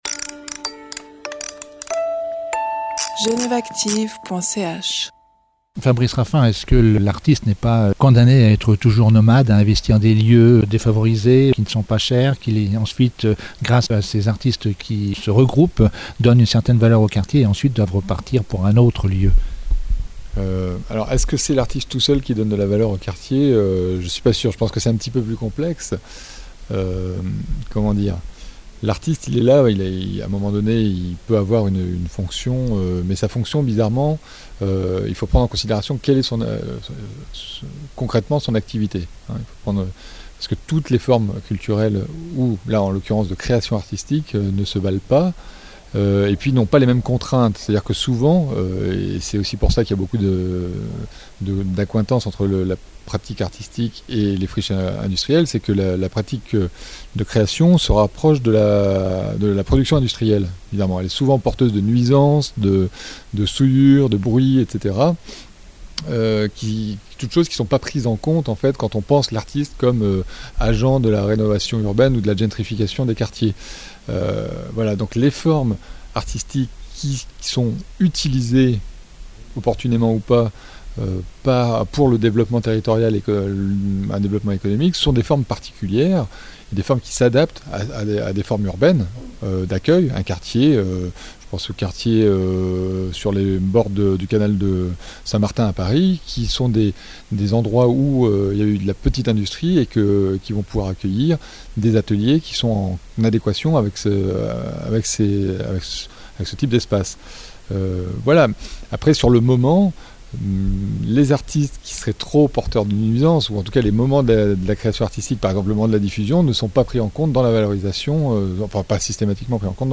Espaces culturels et gentrification : entretien